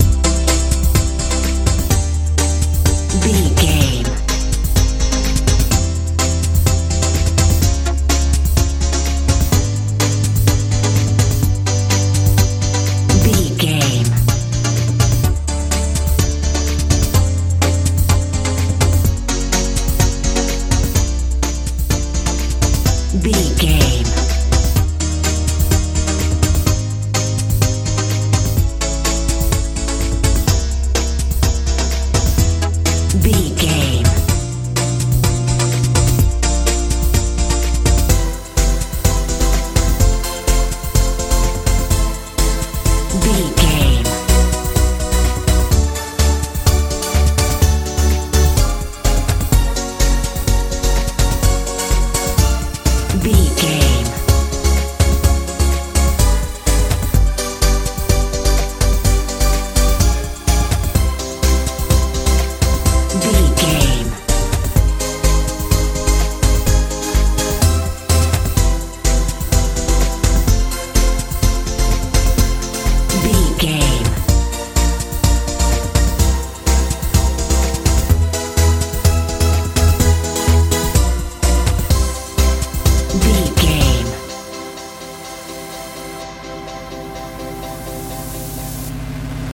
modern dance feel
Ionian/Major
dreamy
fun
bass guitar
drums
synthesiser
80s
90s